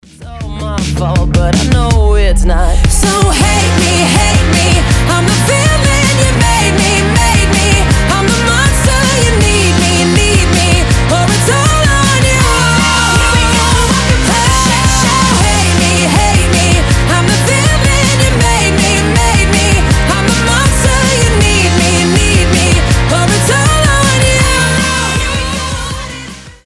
• Качество: 320, Stereo
громкие
Pop Rock
бодрые
поп-панк